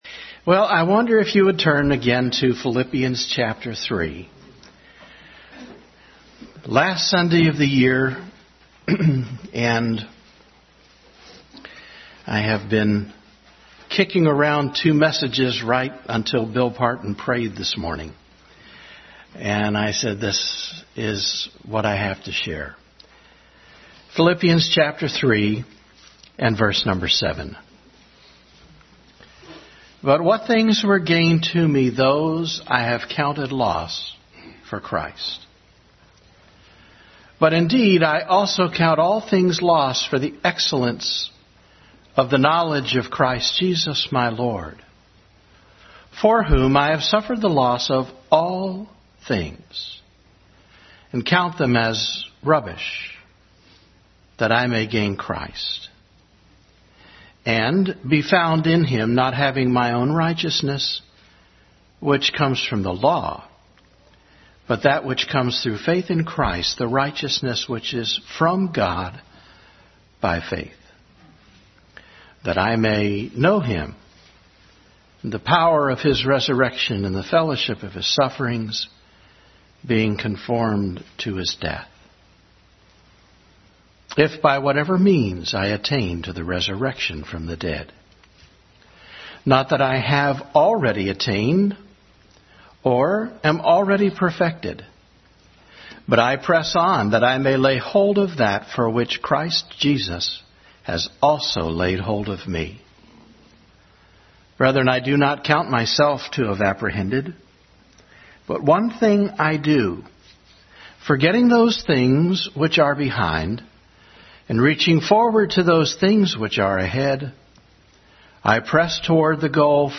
Service Type: Family Bible Hour